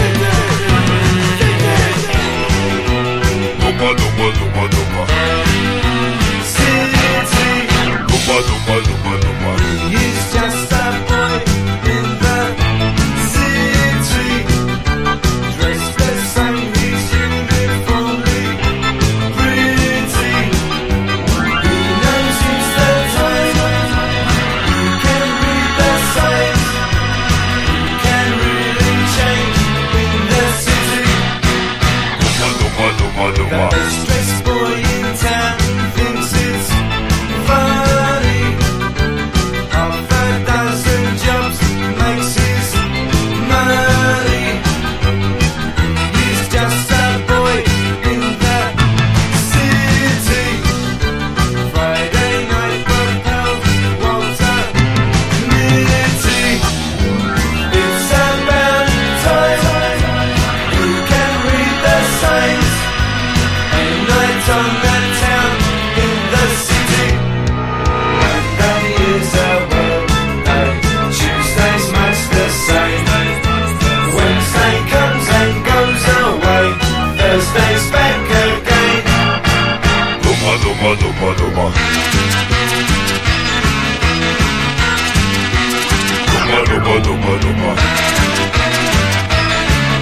1. NEW WAVE >